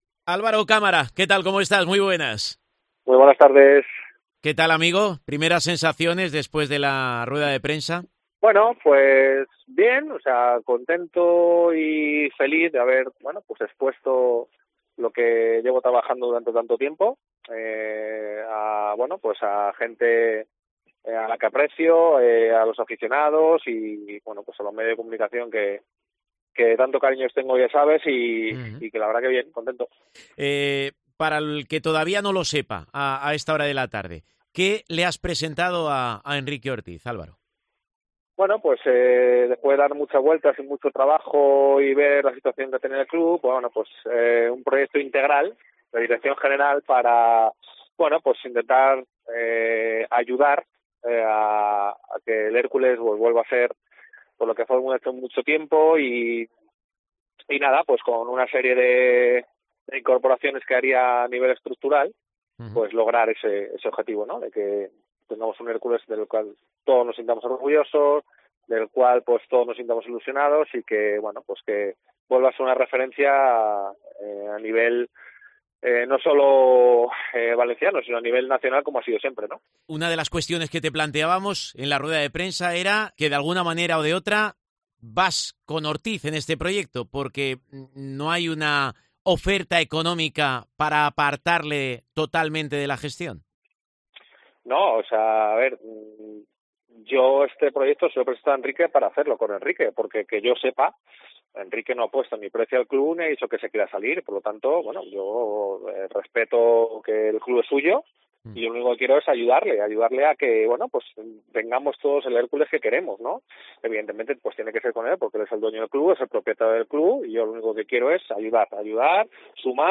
Rodeado de toda la prensa alicantina y de muchos aficionados.